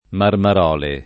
[ marmar 0 le ]